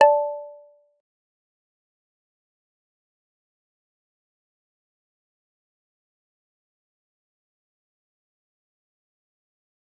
G_Kalimba-D6-mf.wav